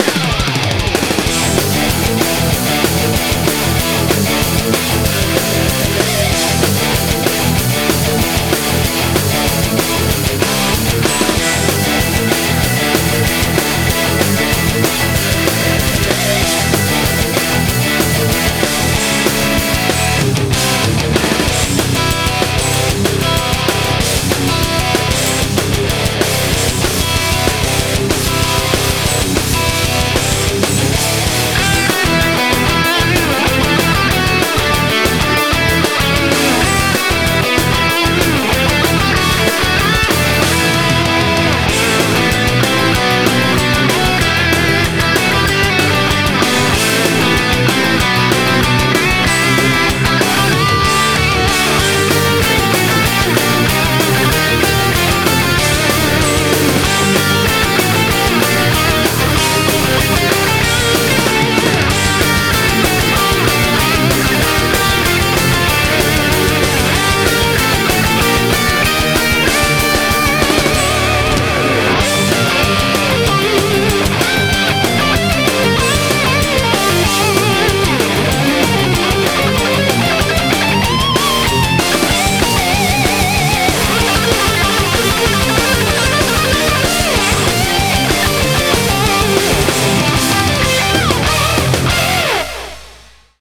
BPM190
A hard rock instrumental.